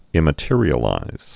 (ĭmə-tîrē-ə-līz)